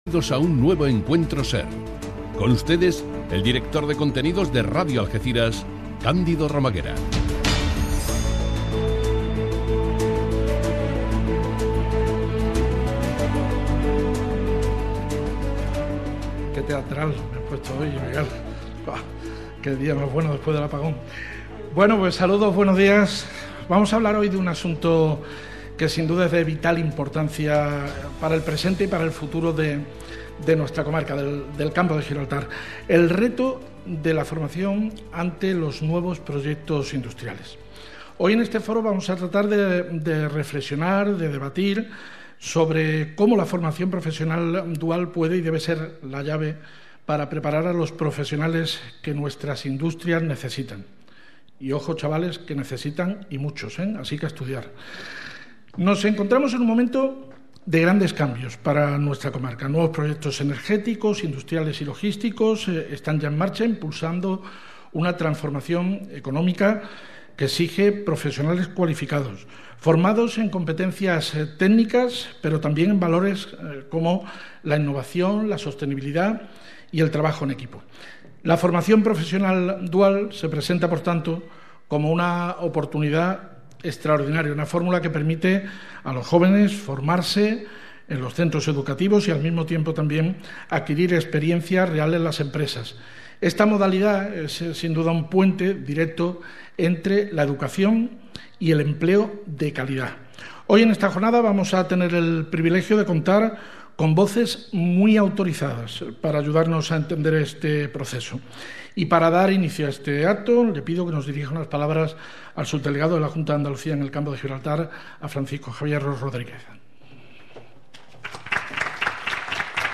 Foro-FP-Dual-organizado-por-Radio-Algeciras-en-el-IES-Isla-Verde.mp3